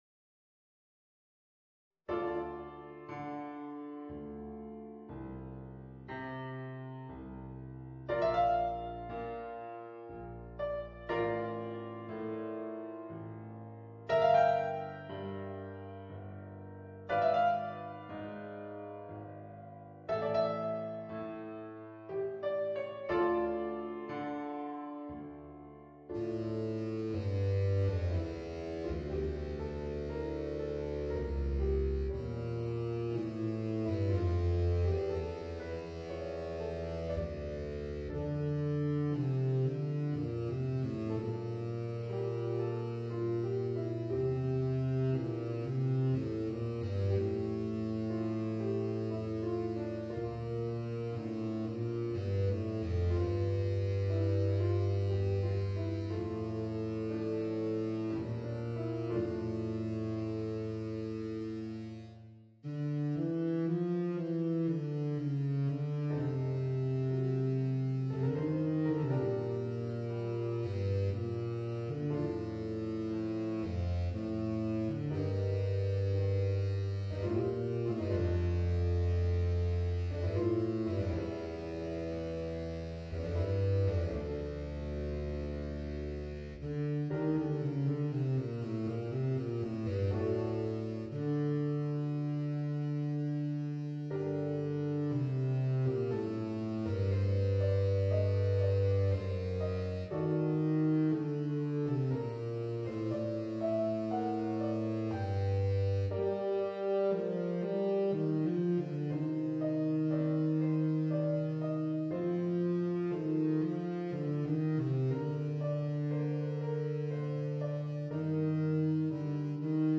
Baritone Saxophone and Piano